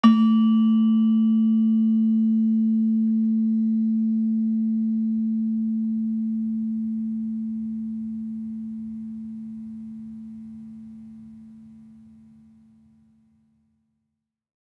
Gamelan Sound Bank
Gender-4-A2-f.wav